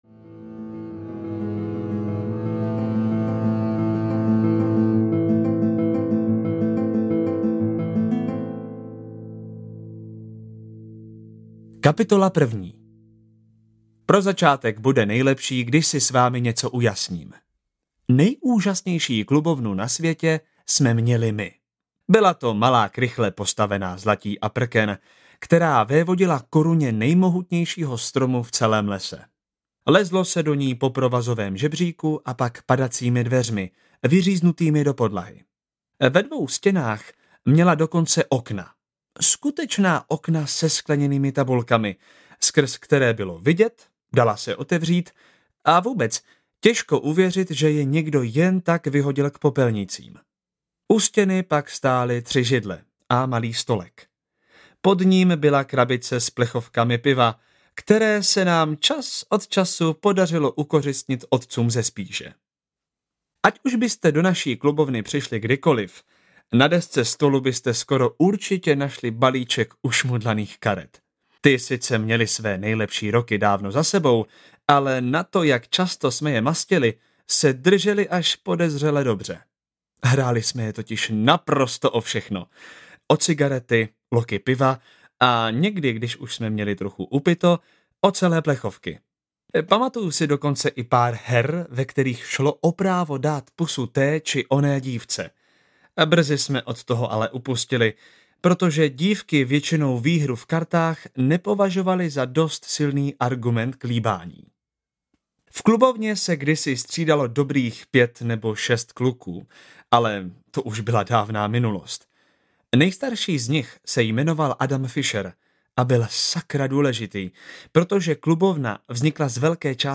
Na Seně audiokniha
Ukázka z knihy